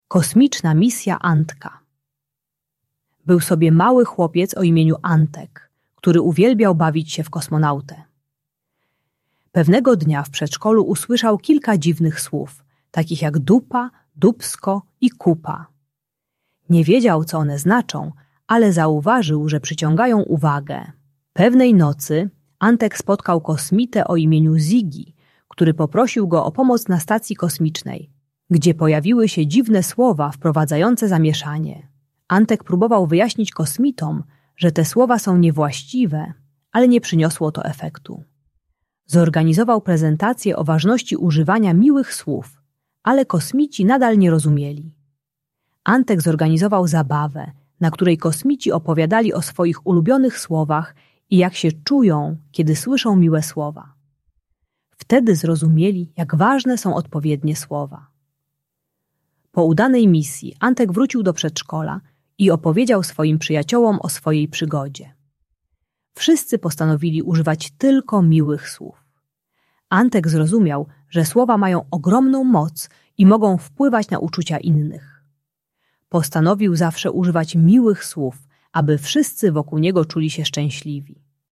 Kosmiczna Misja Antka - Przedszkole | Audiobajka